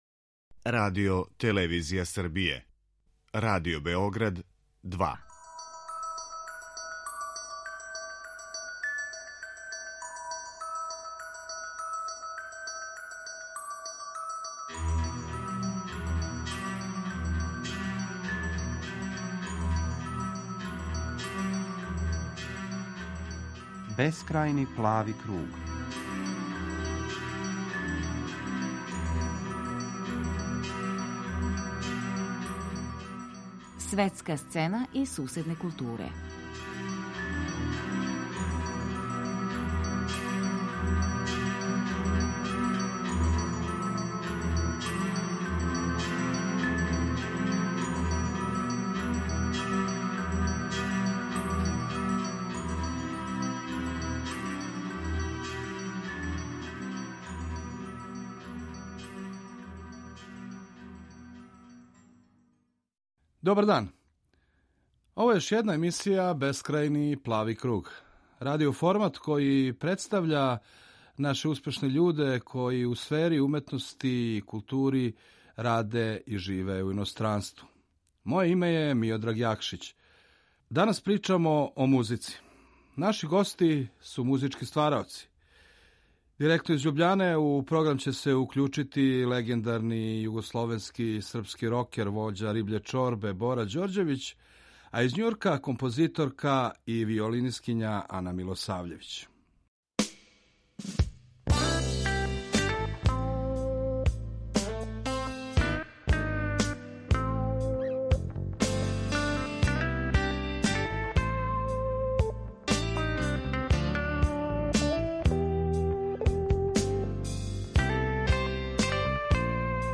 Гости: Бора Ђорђевић